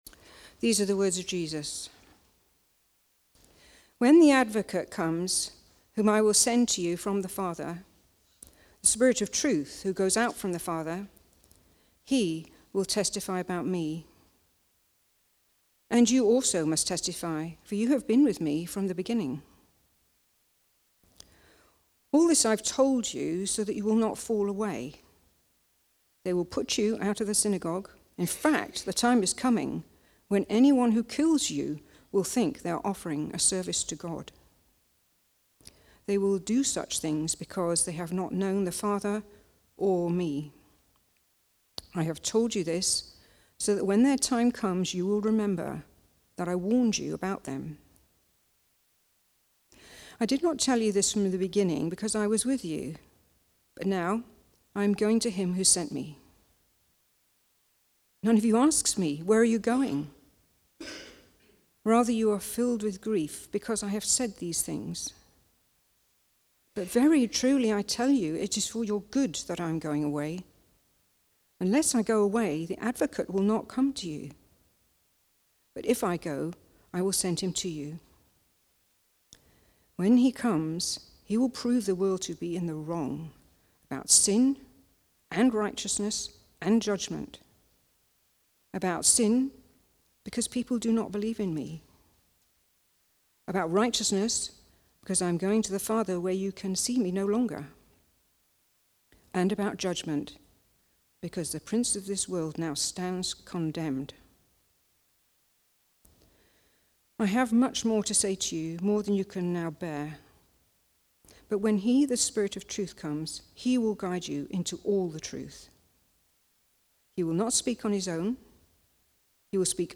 Preaching
The Witness (John 15:26-16:15) from the series Comfort and Joy. Recorded at Woodstock Road Baptist Church on 09 March 2025.